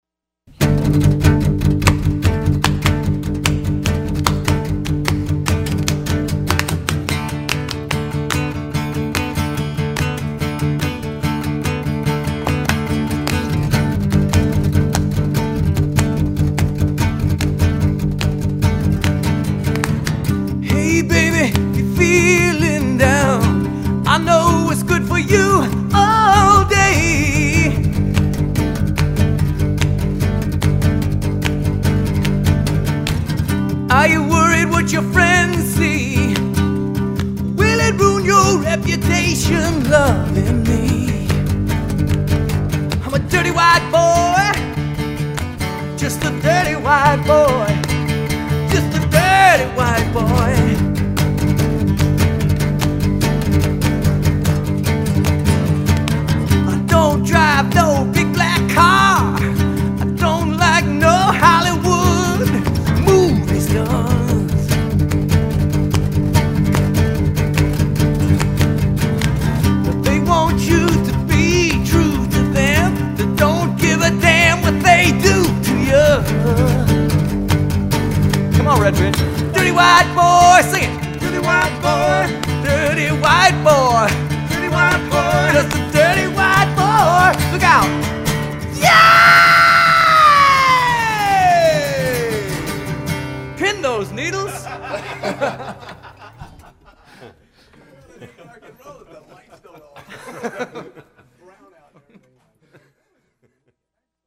lead singer
guitarist
bassist